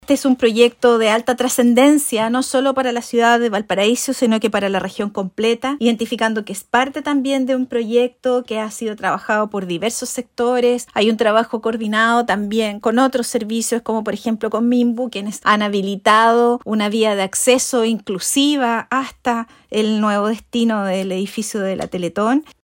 Por su parte, la seremi de Desarrollo Social, Claudia Espinoza, agregó que las calles ya cuentan con una vía, incluso hasta el acceso del futuro edificio.